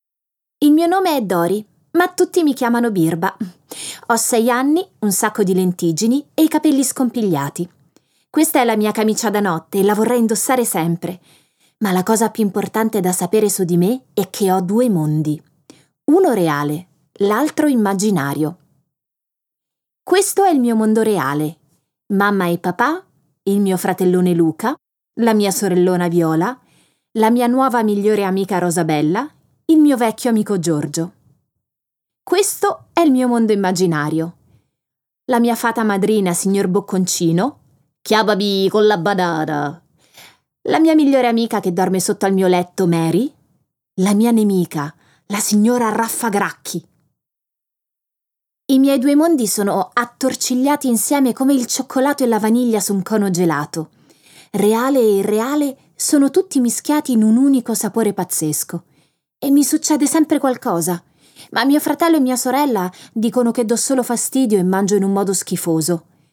letto da Cristiana Capotondi
Versione audiolibro integrale